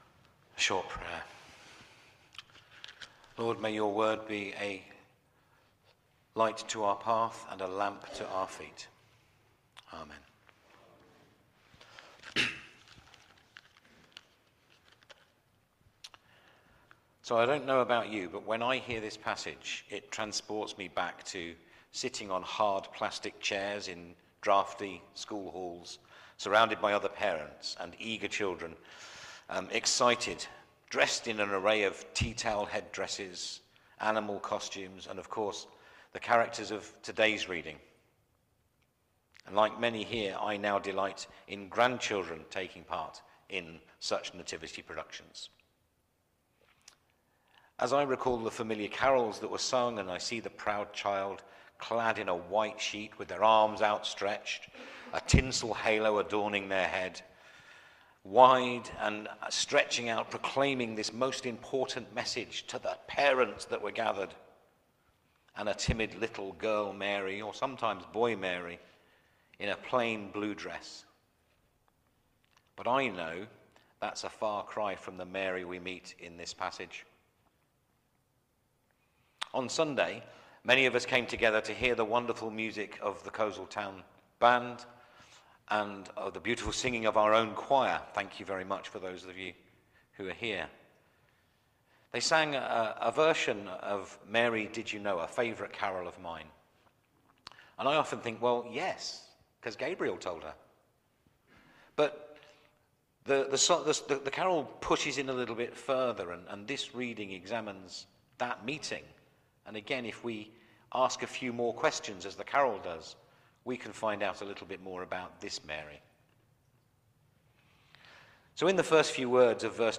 Media for Midweek Communion on Wed 04th Dec 2024 10:00 Speaker
Response to God's call to serve Sermon Search